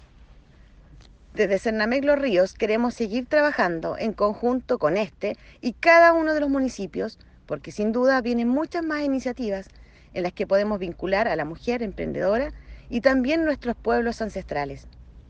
CUÑA-03-DIRECTORA-REGIONAL-SERNAMEG-.mp3